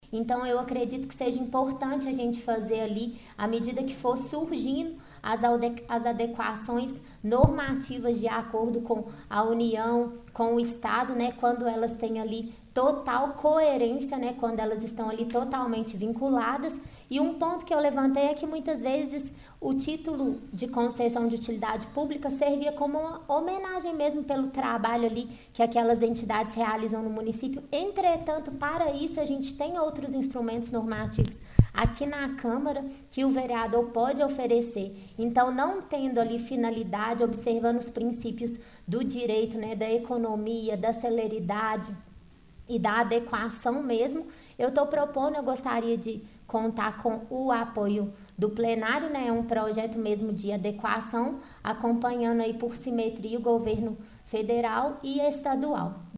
O Portal GRNEWS acompanhou no plenário da Câmara de Vereadores de Pará de Minas mais uma reunião ordinária na noite desta Quarta-Feira de Cinzas, 02 de março.